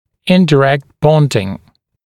[ˌɪndɪ’rekt ‘bɔndɪŋ] [-daɪ-][ˌинди’рэкт ‘бондин] [-дай-]непрямое приклеивание, непрямая фиксация